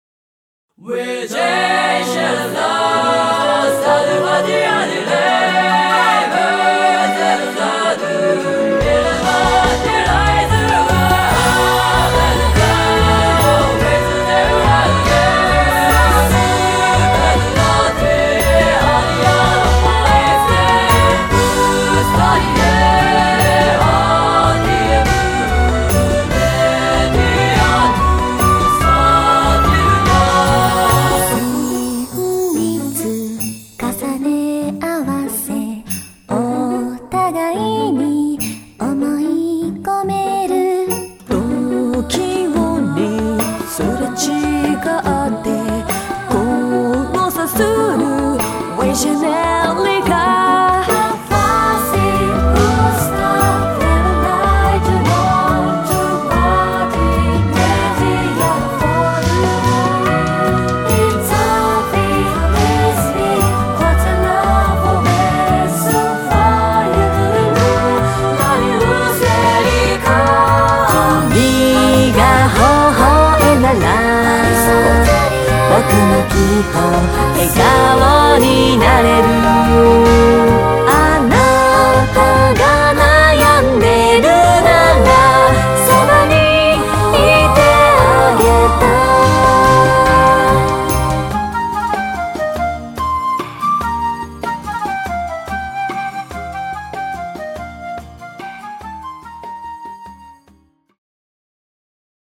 天使語のコーラスと民族調の楽曲で紡ぐ学園生活をお楽しみください。